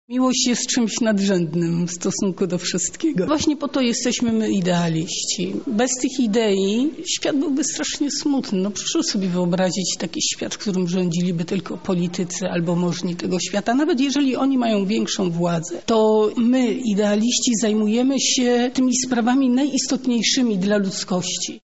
Pod takim hasłem odbyła się dziś konferencja z okazji 10-lecia Akademickiego Punktu Wolontariatu.
„Błogosławieni, którzy łakną sprawiedliwości, czyli o tych, którzy biorą sprawy we własne ręce” – to tytuł wystąpienia Janiny Ochojskiej